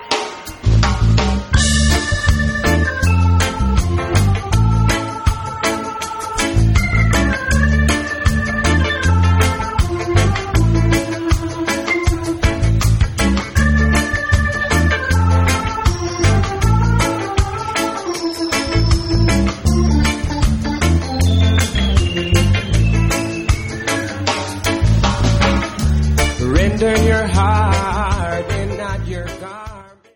Funk
Jamband
World Rhythms